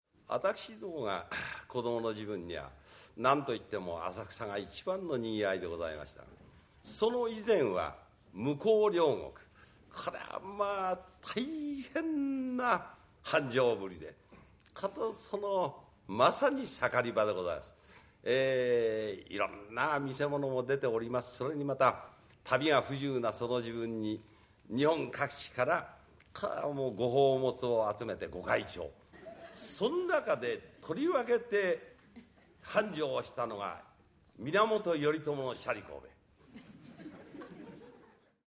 圓楽全盛期の熱演が聴けるCD「至芸 五代目三遊亭圓楽特選落語名演集」より抜粋
平成10年のNHK「日本の話芸」からの放送音源。
粋な語りと豊かな表現で聴かせる、圓楽ならではの話芸をたっぷり楽しめる。